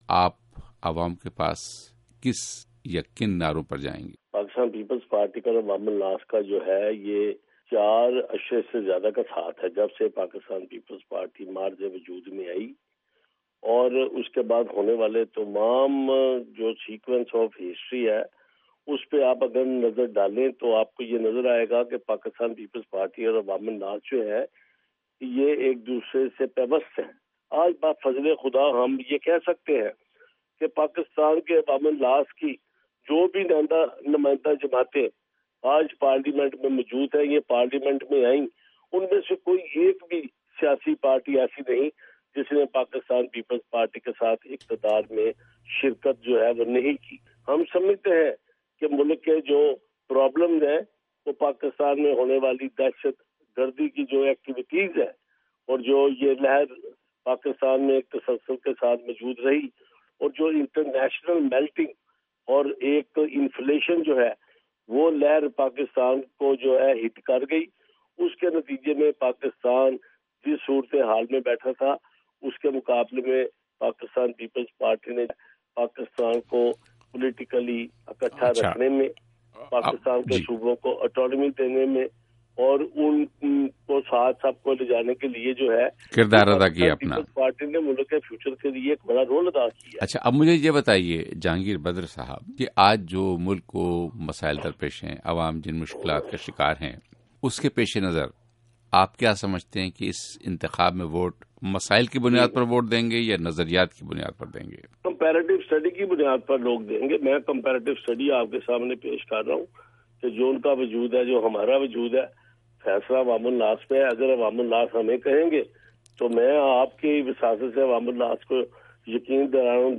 پیپلز پارٹی کے سکریٹری جنرل کا انٹرویو